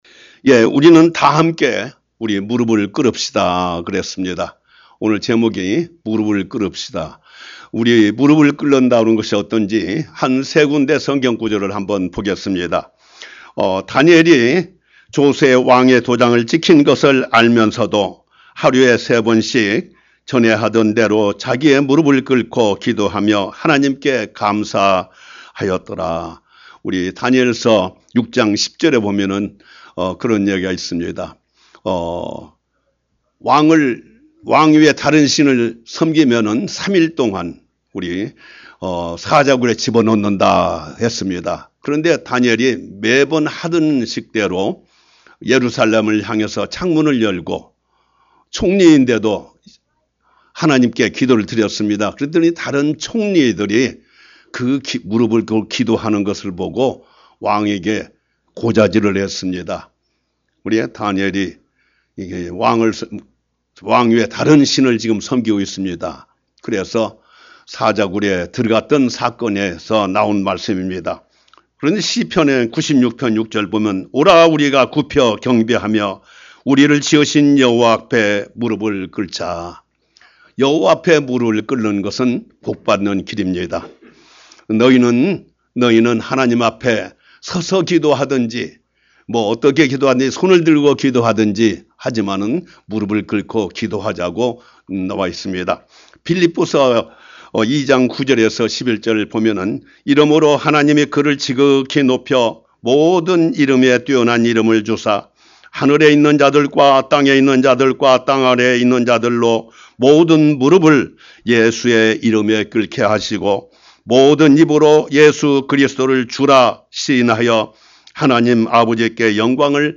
Sermon - 우리 함께 무릎을 꿇자 Let’s kneel before the Lord